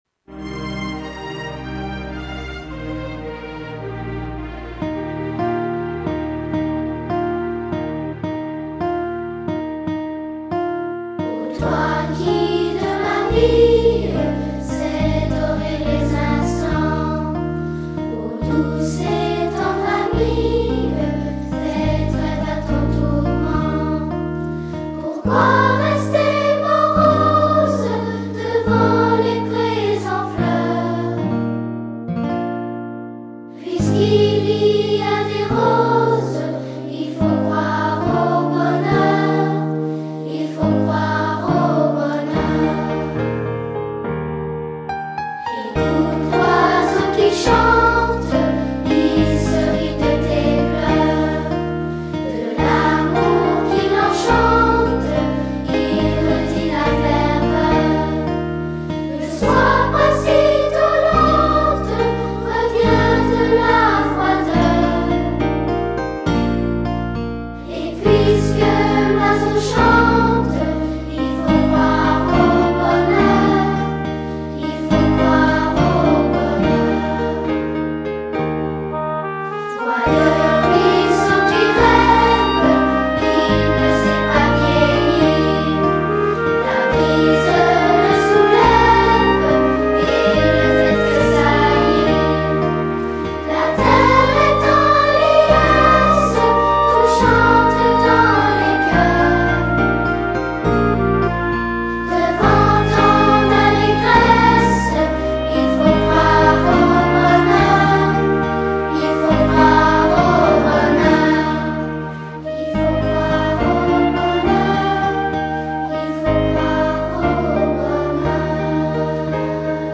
Chants de la Chorale de Cycle 3 - Année 2014/15
Version chantée :